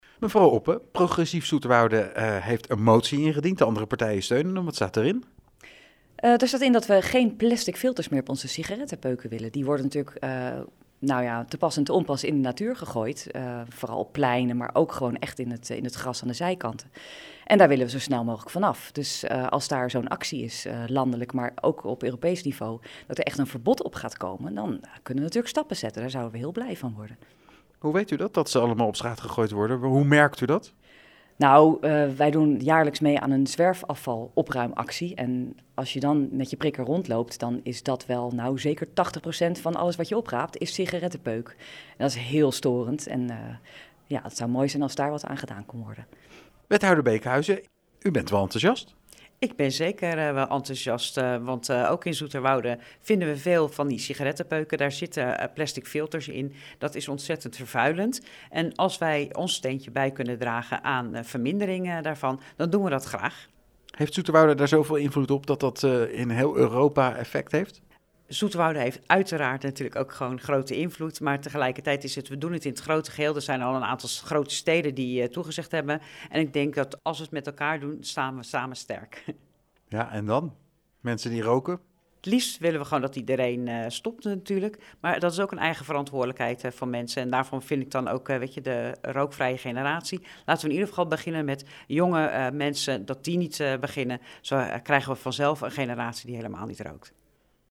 PZ-raadslid Milica Oppe en wethouder Angelique Beekhuizen over de campagne ‘No (Plastic) filter’: